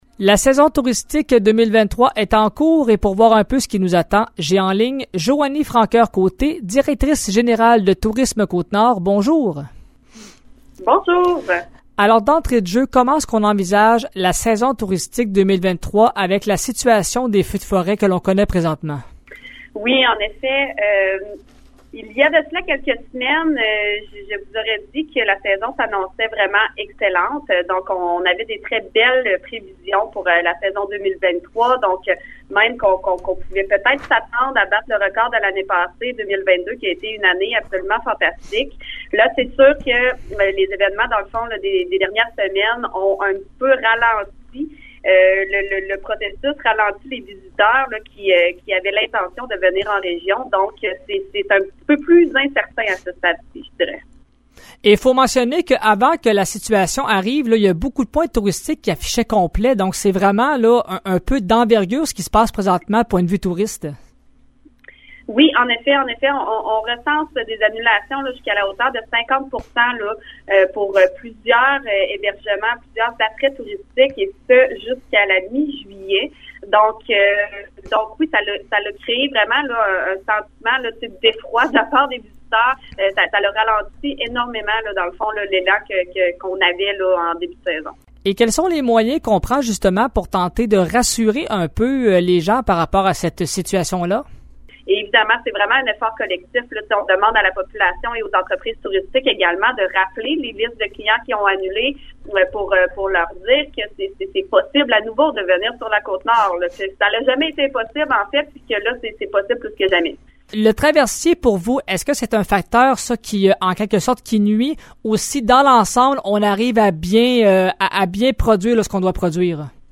L'entrevue